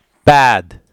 [bæˑd]